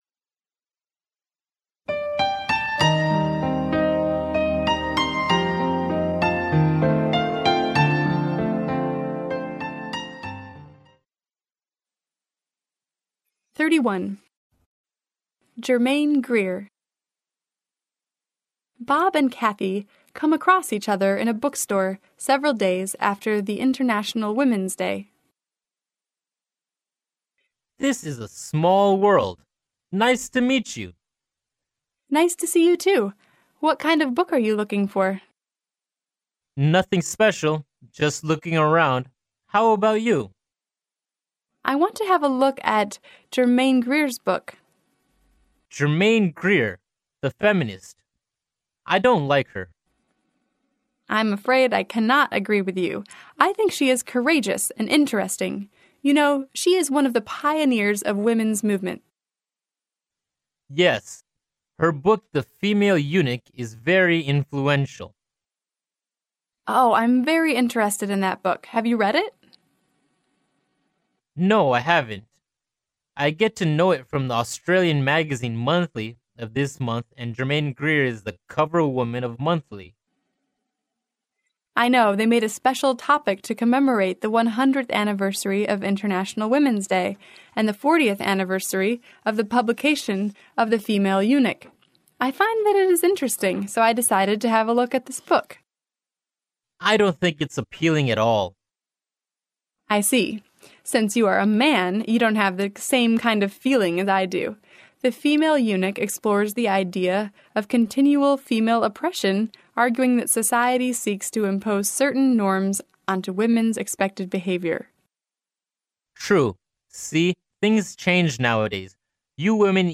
剑桥大学校园英语情景对话31：女权主义者的声音（mp3+中英）